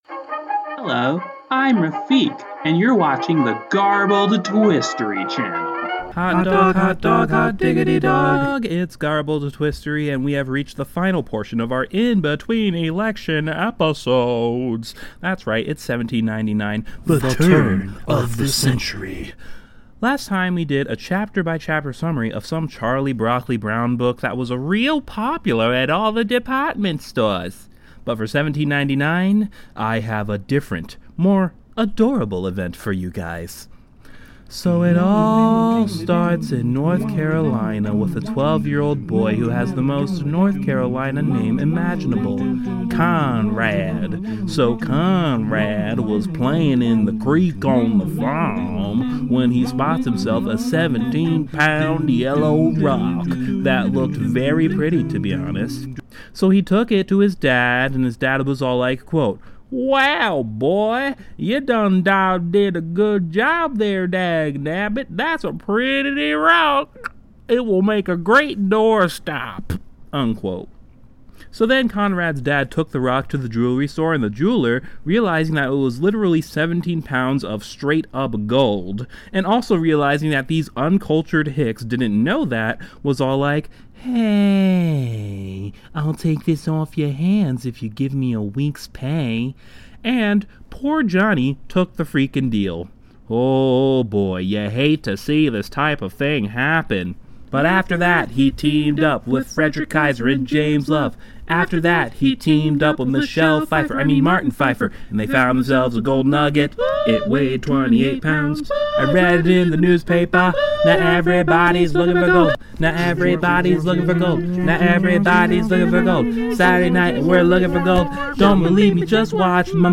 Oh, and there’s a fun little musical numbah in there heheh.